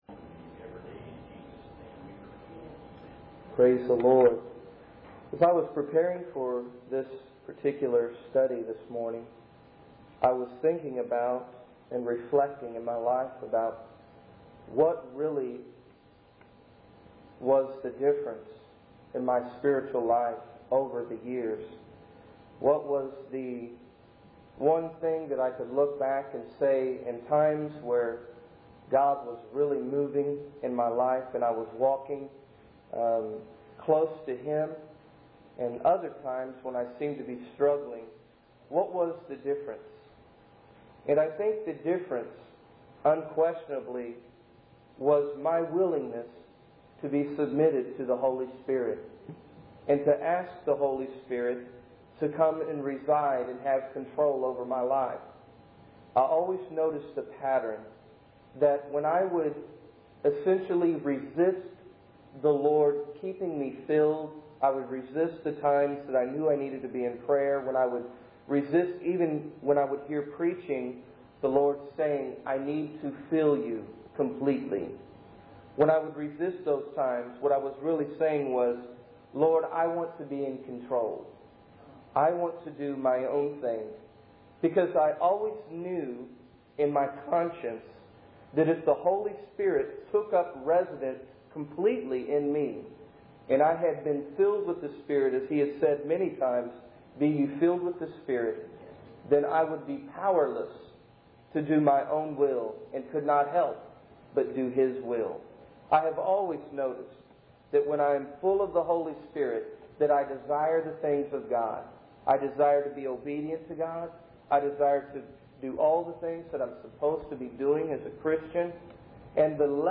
In this sermon, the speaker reflects on his personal journey as a classical Pentecostal and emphasizes the importance of being filled with the Holy Ghost. He expresses concern about the current state of the world and the lack of holiness in his generation.